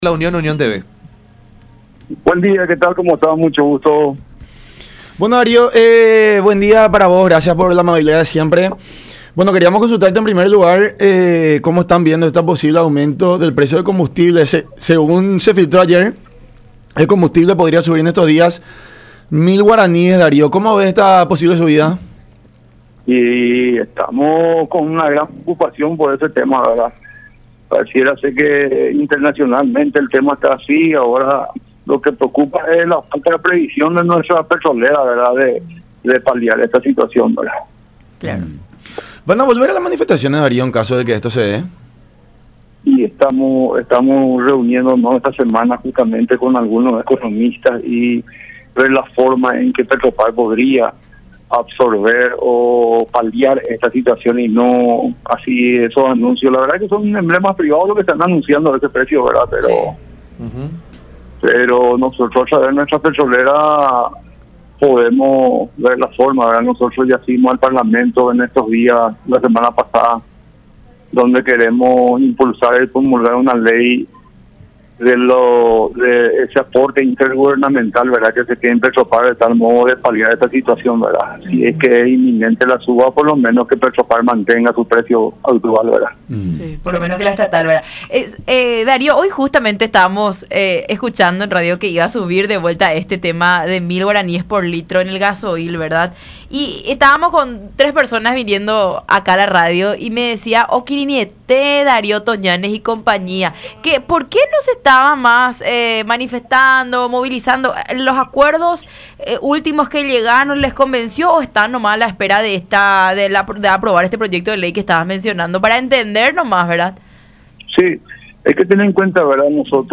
en diálogo con La Unión Hace La Fuerza por Unión TV y radio La Unión.